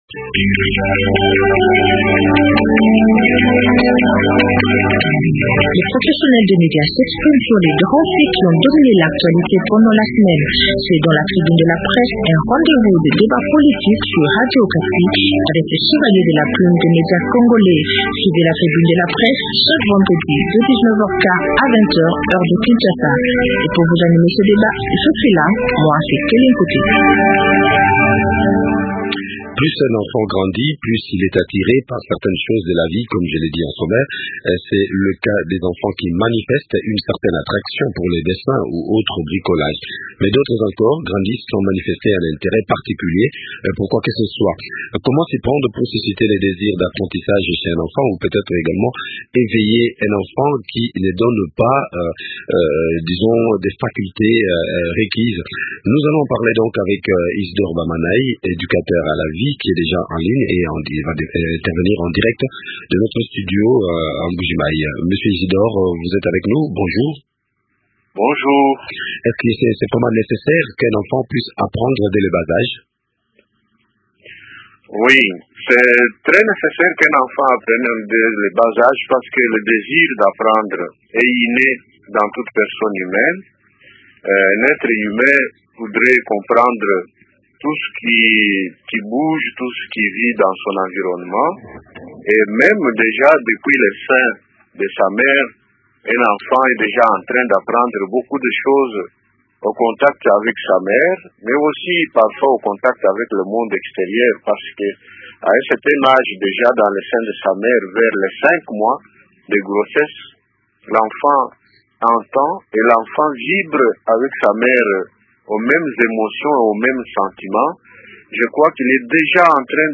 En pareille circonstance, comment s’y prendre pour susciter le désir d’apprentissage chez cet enfant ? Eléments de réponse dans cette interview